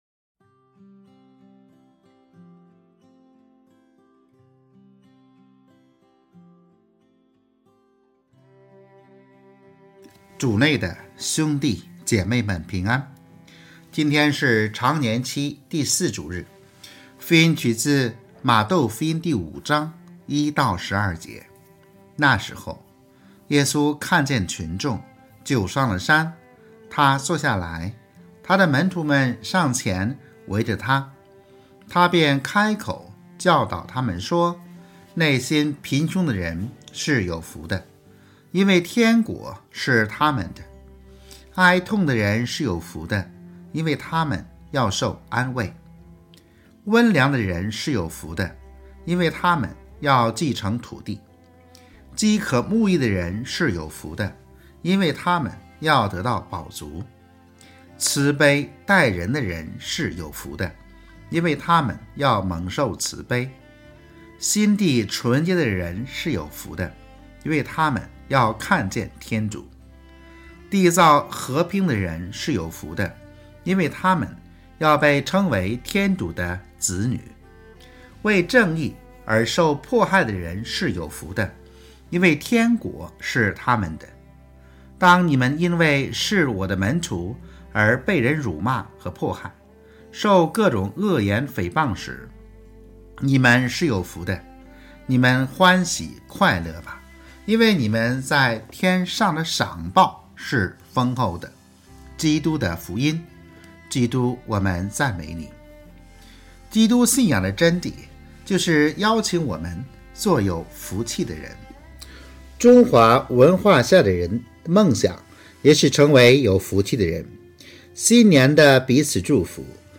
【主日证道】| 做有福气的人（甲常4主日）